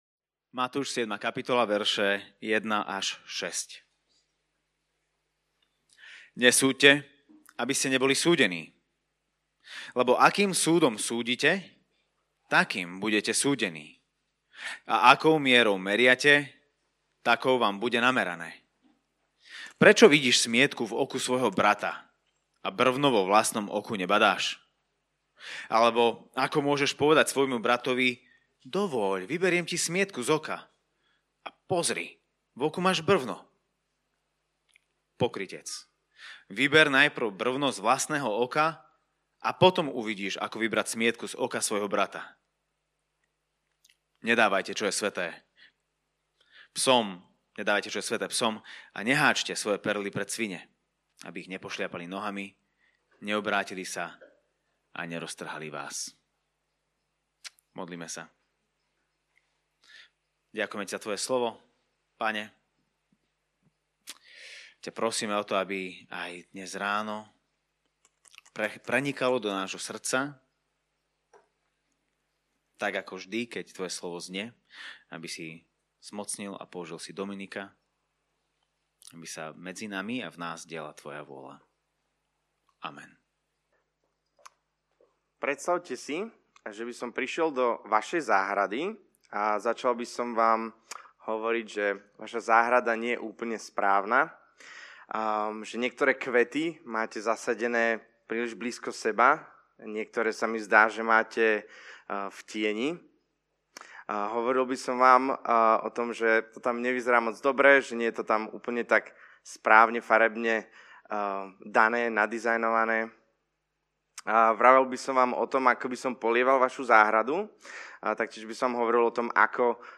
Podcasty Kázne zboru CB Trnava Ježiš vraví: dovoľ, vyberiem ti z oka…